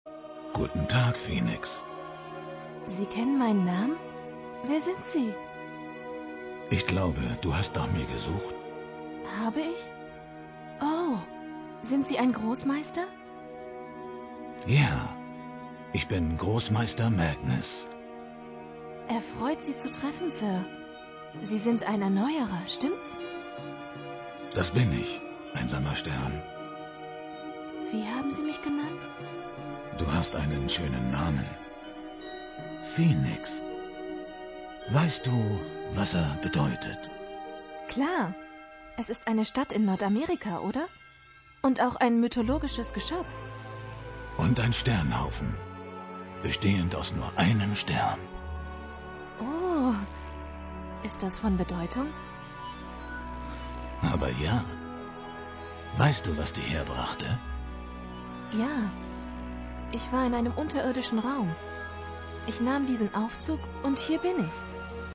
Kein Dialekt
Sprechprobe: eLearning (Muttersprache):
english (us) voice over artist